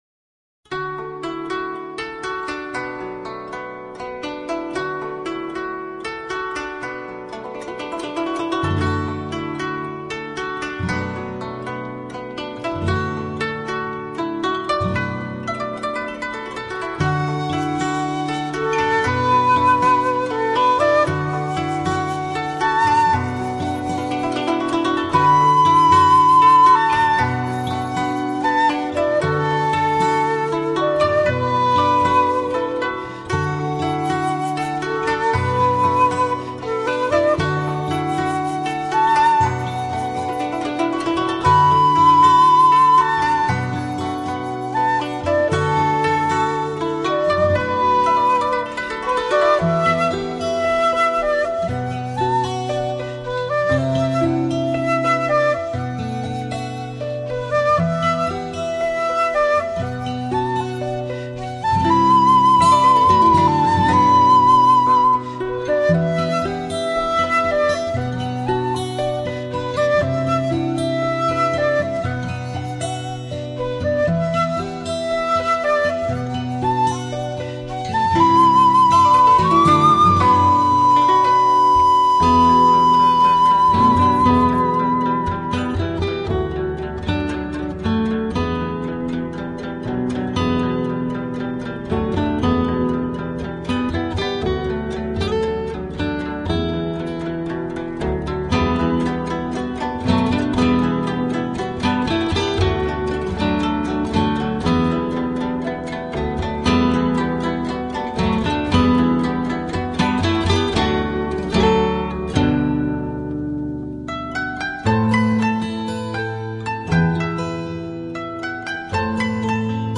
尺八、古筝、吉他